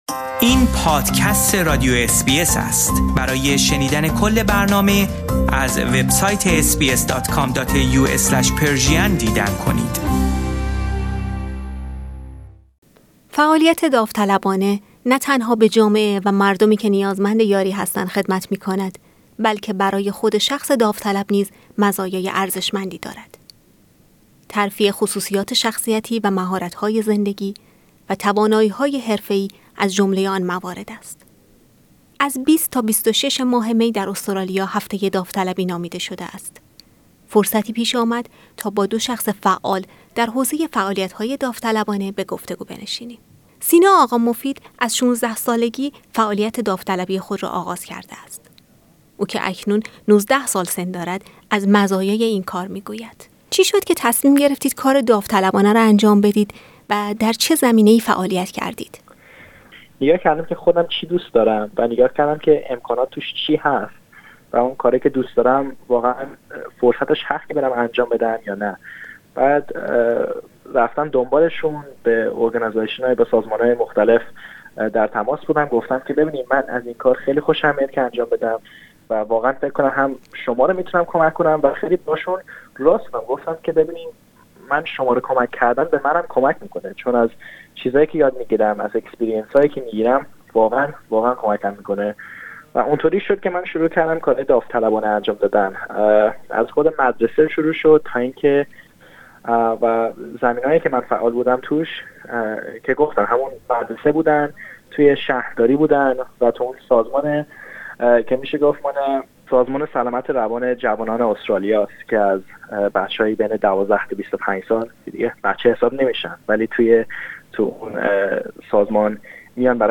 هفته ملی داوطلبان و گفتگو با داوطلبان فعال ایرانی در استرالیا
فرصتی پیش آمد تا با دو شخص فعال در حوزه فعالیت های داوطلبانه گفتگویی داشته باشیم.